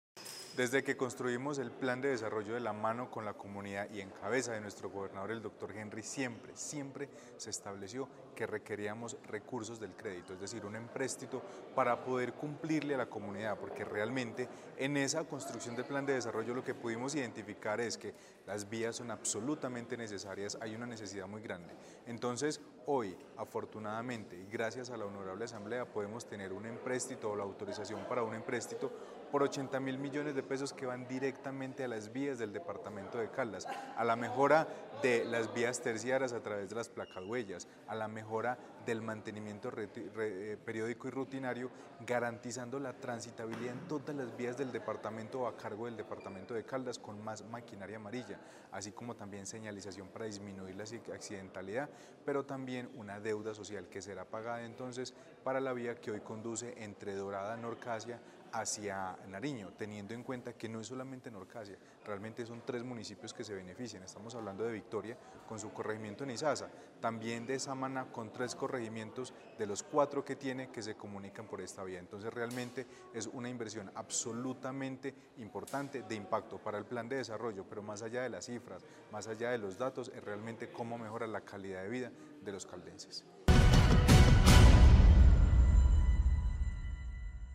Secretario de Planeación, Carlos Anderson García.